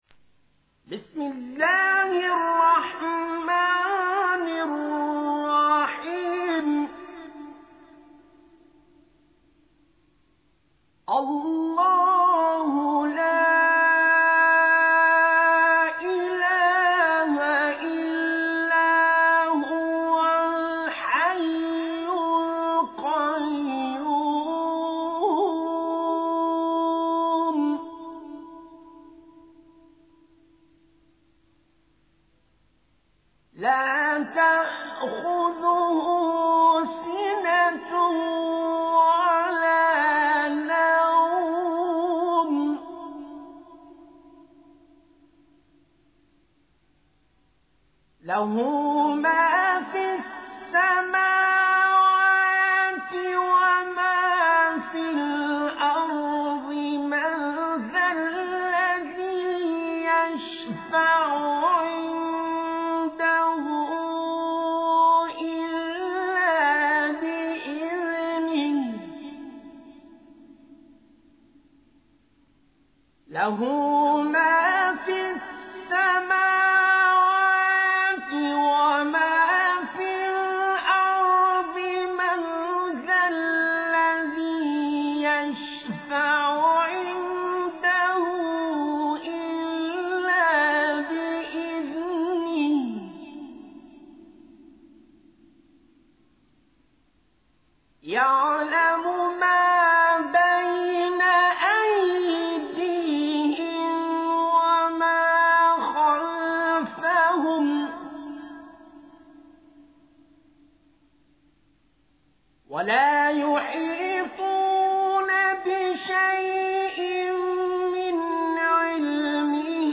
récitation mp3